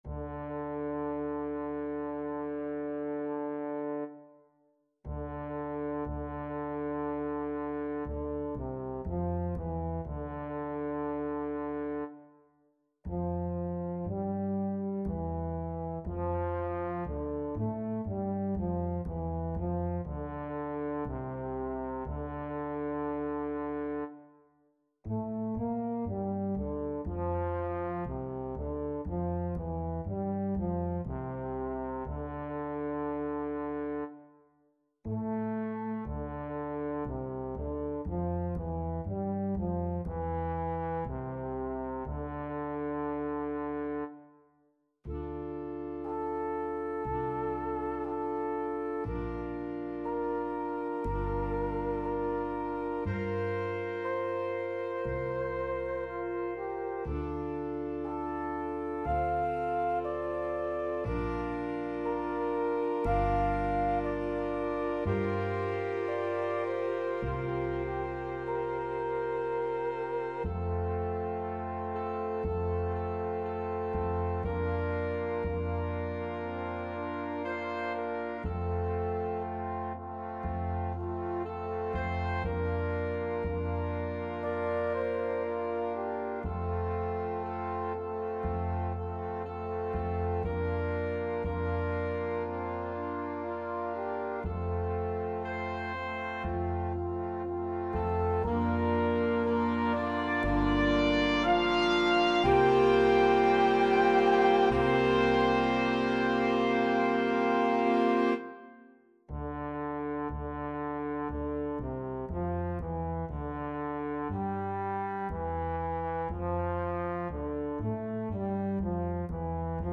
Skladby pro Big Band / Big Band Scores
computer demo